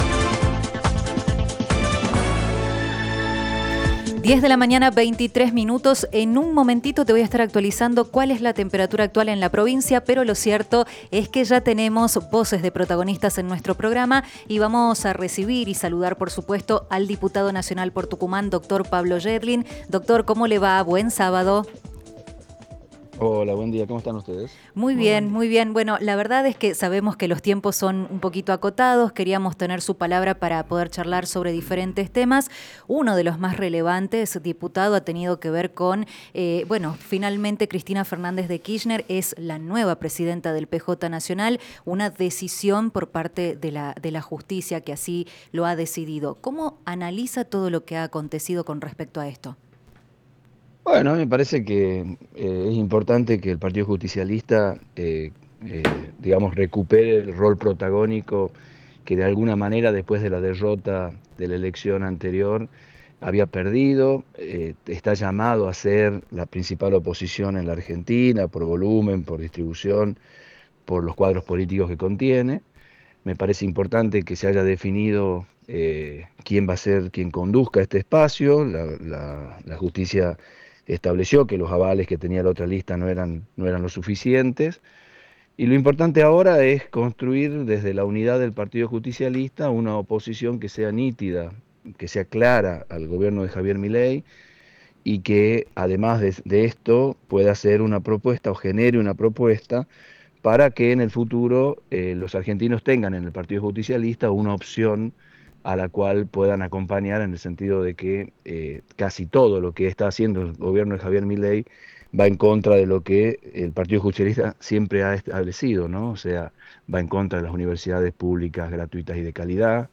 El diputado Pablo Yedlin en diálogo telefónico con Café Prensa se refirió a la designación de Cristina Kirchner en la presidencia del PJ.